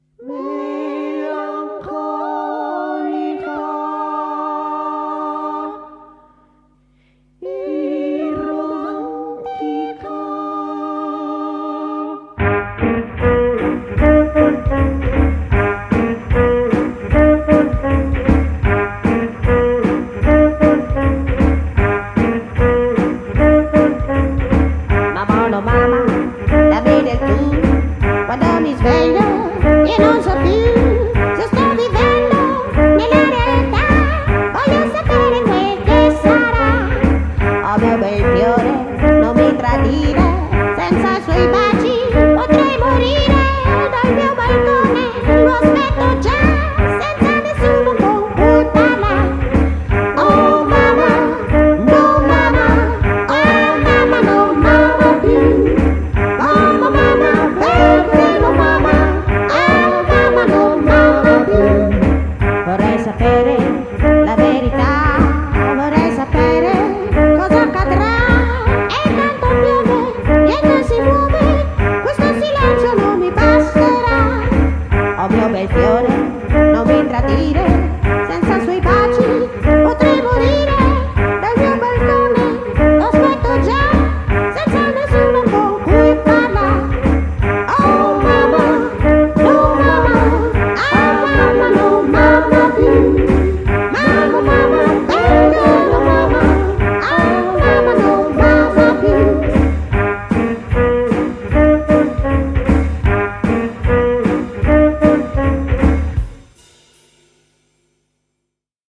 BPM153
Audio QualityCut From Video